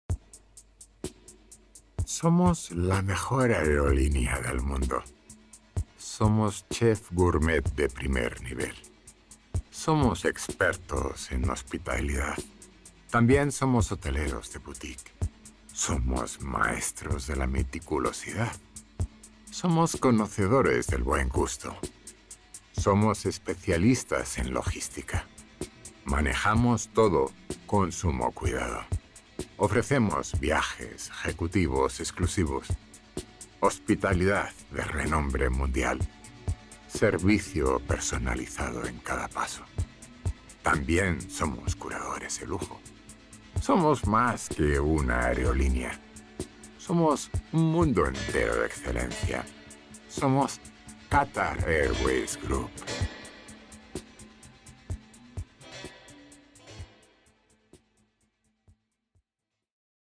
Glatt
Warm
Faszinierend